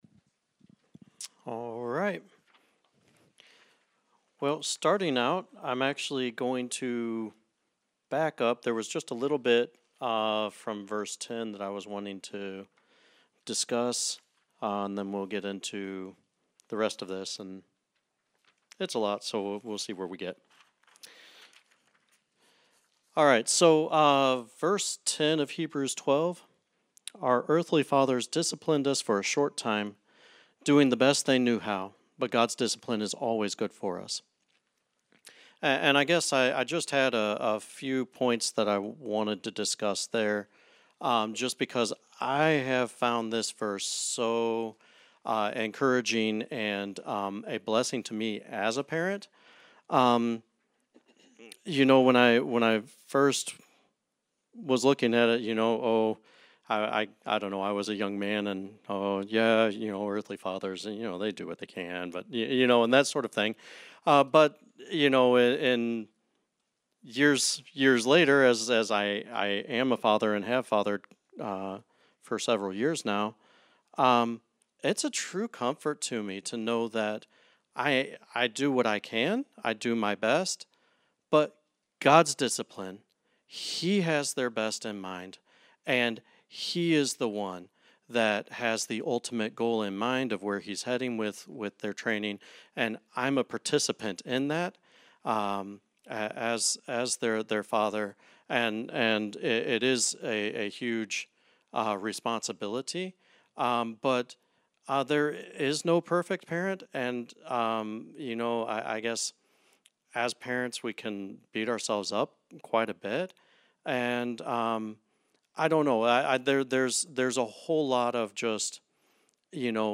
Service Type: Wednesday Night